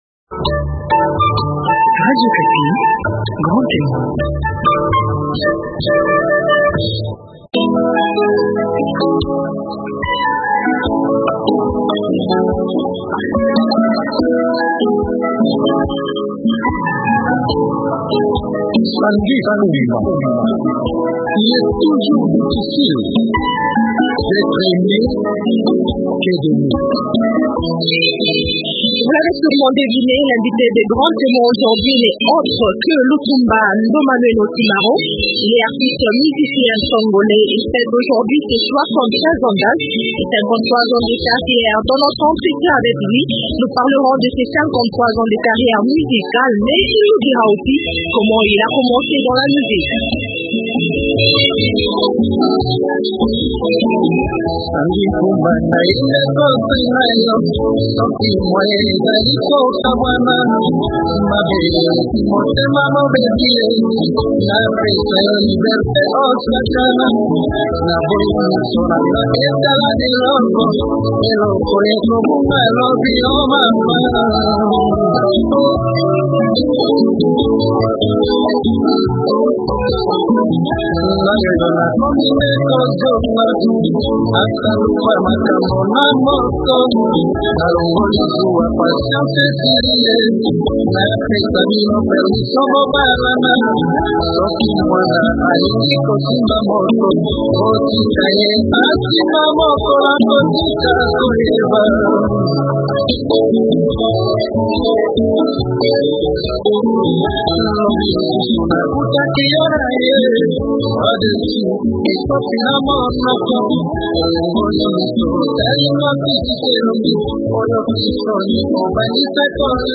Lutumba Ndomanueno Simaro, auteur compositeur Congolais, est celui que Grand témoin reçoit cette semaine.
Dans son entretien